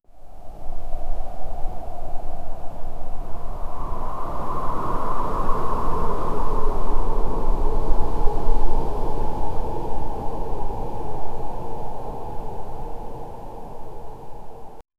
We fly ashes in the air 0:15 Created Apr 15, 2025 2:50 PM Haunting wind blowing across an empty battlefield with soft ashes falling and echoing silence 0:15 Created May 17, 2025 9:32 AM
haunting-wind-blowing-acr-lwxougmz.wav